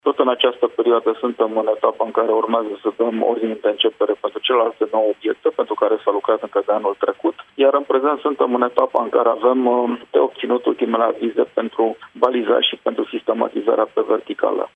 In prezent, sustine presedintele Consiliului Judetean, se lucreaza la studiul de fezabilitate pentru sistemele de radio-navigatie, iar cei care vor opera acest aeroporat vor fi reprezentantii ROMATSA: